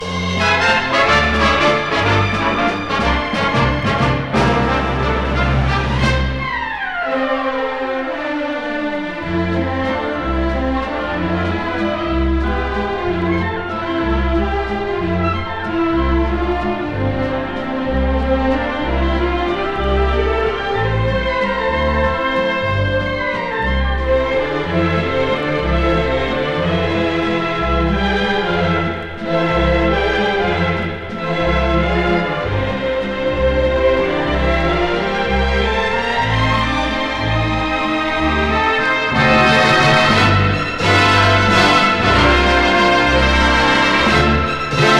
Jazz, Pop, Easy Listening　UK　12inchレコード　33rpm　Stereo